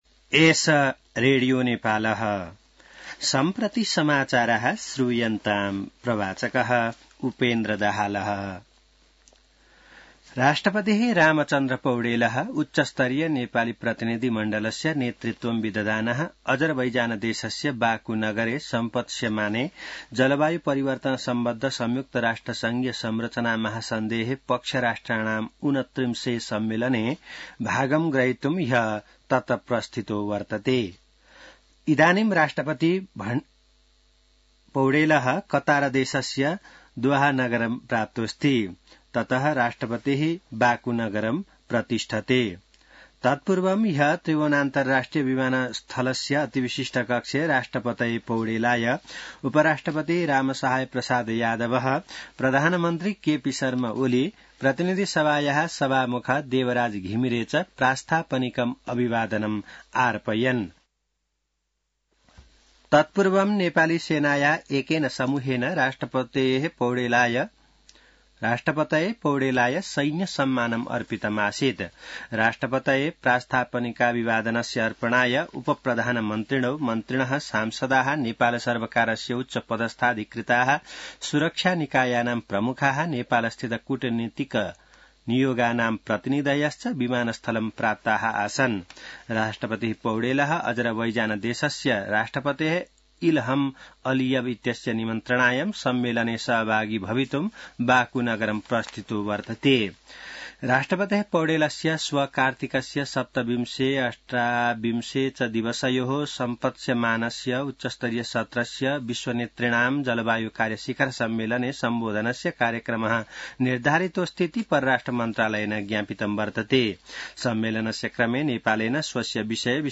संस्कृत समाचार : २७ कार्तिक , २०८१